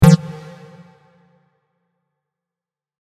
Blip 4.mp3